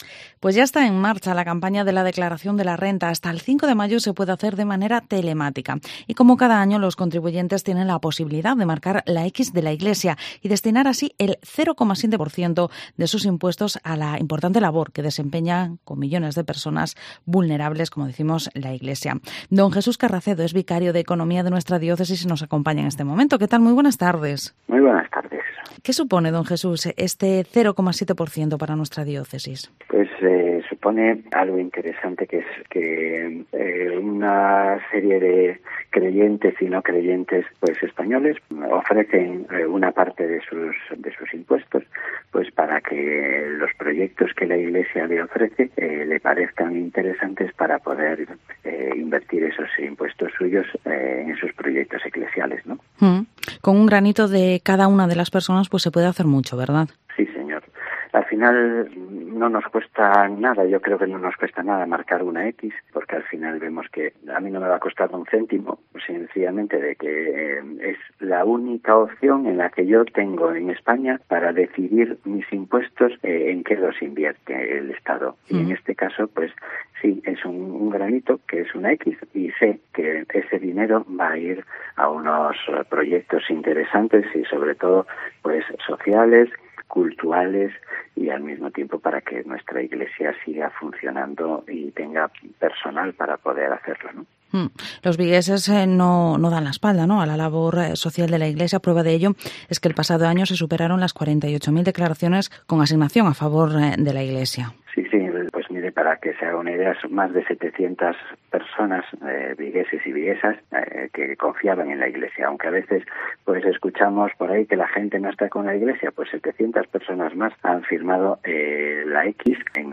Vigo Entrevista ¿Qué implica marcar la X de la Iglesia en la declaración de la renta?